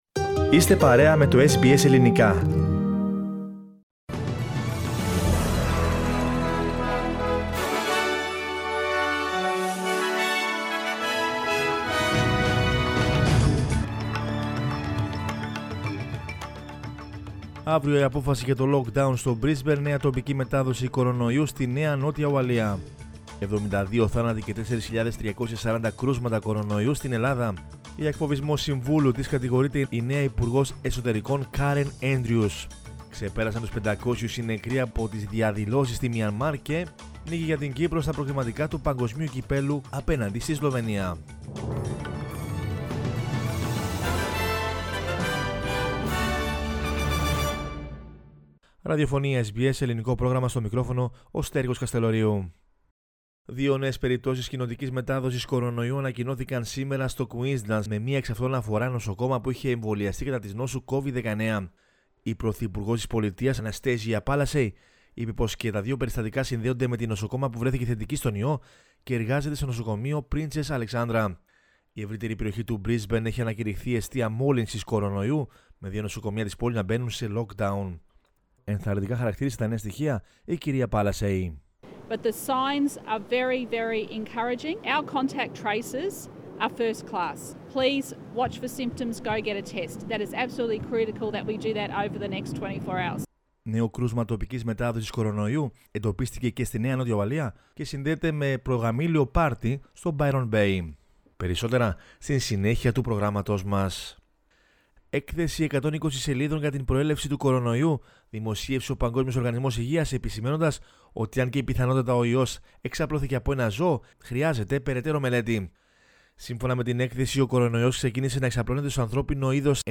News in Greek from Australia, Greece, Cyprus and the world is the news bulletin of Wednesday 31 March 2021.